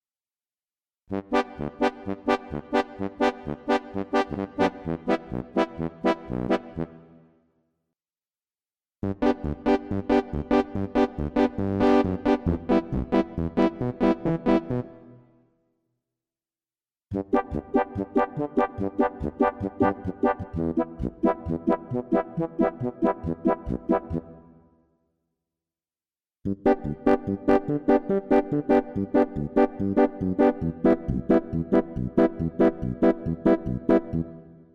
• Samples de los mejores Tubas del PSS470
• Tuba Original PSS-470: Samples directos del hardware original, capturando ese tono “pastoso” y con cuerpo que es imposible de imitar con sintetizadores estándar.
• Fidelidad Auténtica: Grabado en alta resolución para preservar el carácter lo-fi pero potente del PSS-470 original.
Tubas-PSS470.mp3